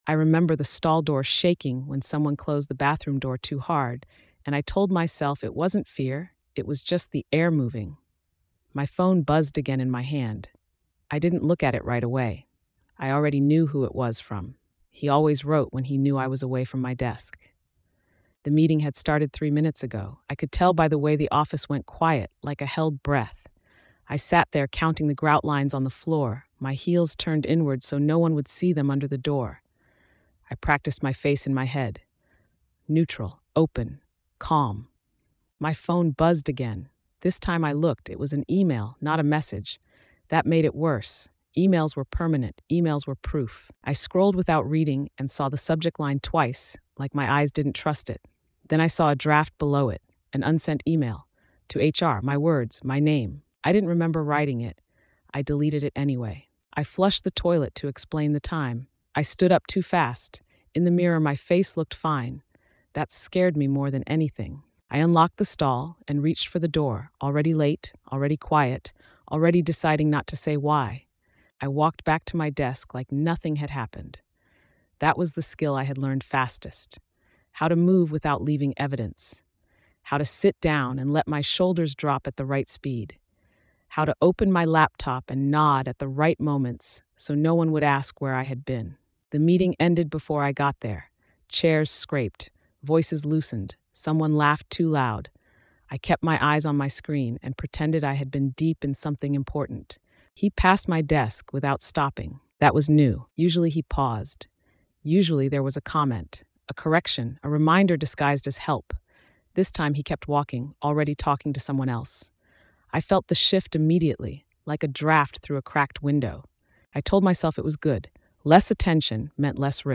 In Abusive Bosses, Silent Offices, a woman navigates the quiet erosion of power inside a corporate environment where abuse hides behind professionalism and plausible deniability. Told entirely in her own voice, the story traces how silence becomes both a shield and a trap, as subtle retaliation replaces overt conflict.